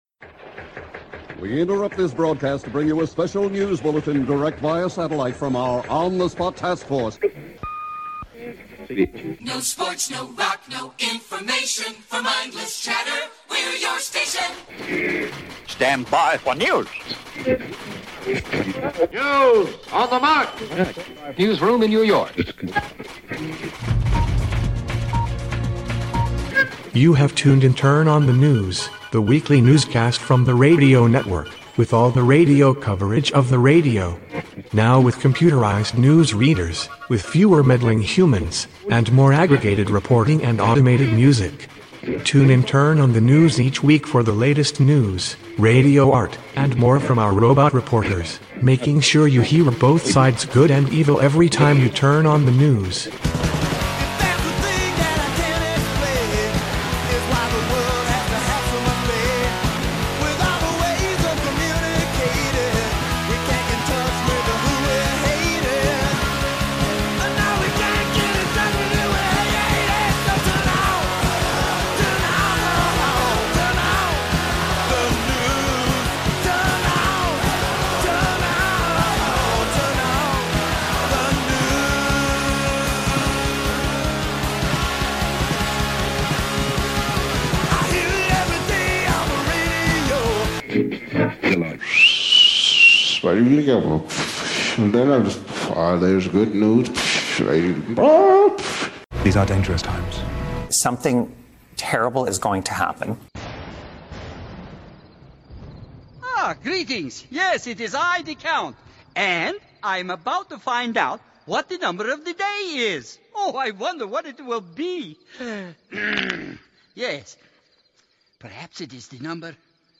Clips and excerpts from The Count; Chris Jansing; Stephen Colbert; Seth Meyers; Donald Drumpf; John Oliver; Joe Biden; Marjorie Taylor Greene; Steve Doocy; Nikki Haley; Lindsey Graham; Chris Hayes; and Peter Navarro.
Now with computerized news readers, and fewer meddling reporters, plus aggregated reporting, and automated music.
It is often a mash-up of the week's news, and sometimes a radio news fantasy with song parodies and covers similar to "Dr. Demento" and comedy skits and more.